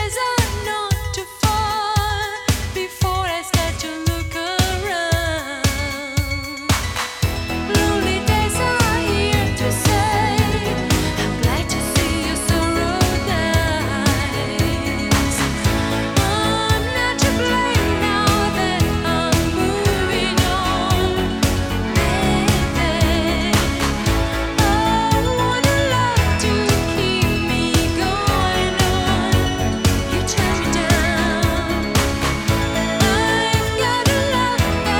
Саксофон
лёгкий свинг
1992-01-01 Жанр: Джаз Длительность